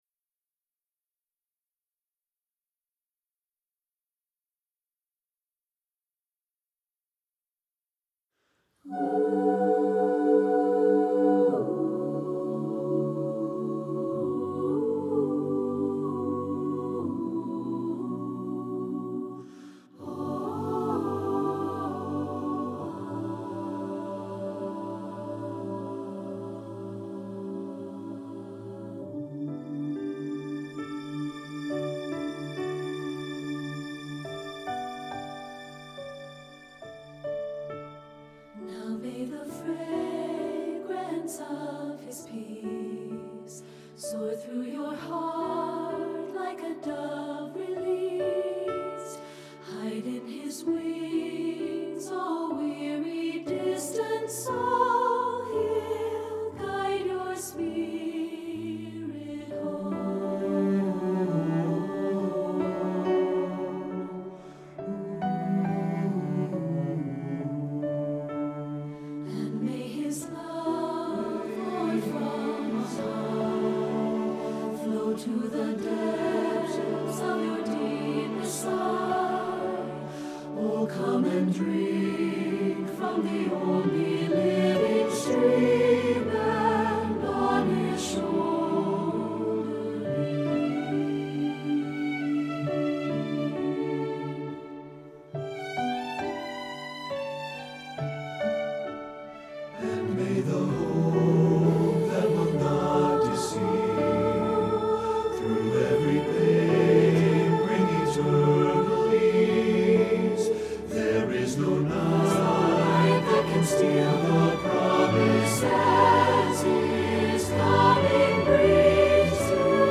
with remarkable restraint and sensitivity
Voicing SATB